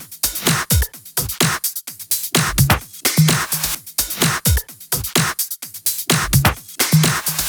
VFH2 128BPM Capone Kit 2.wav